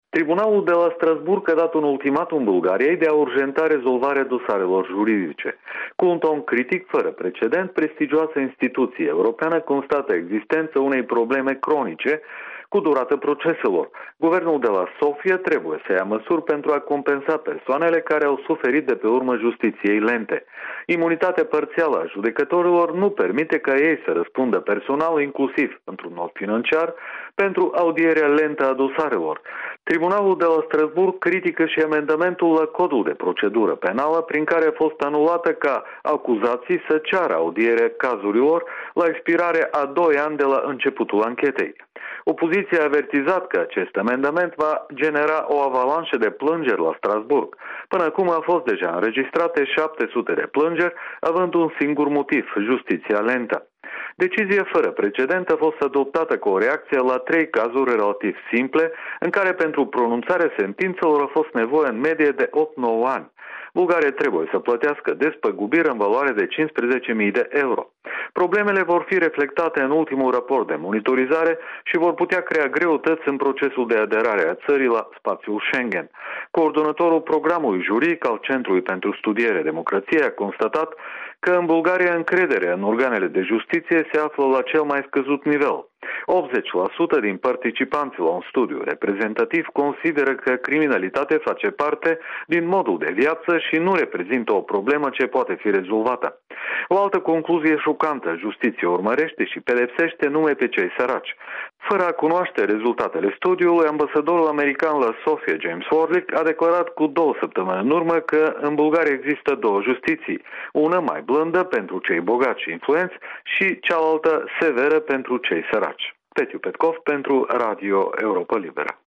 Corespondență de la Sofia: critici la adresa justiției bulgare